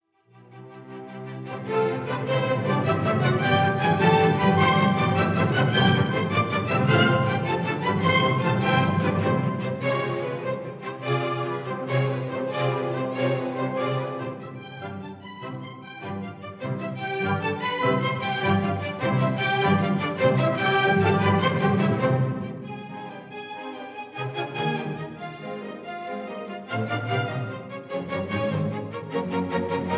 minuet and trio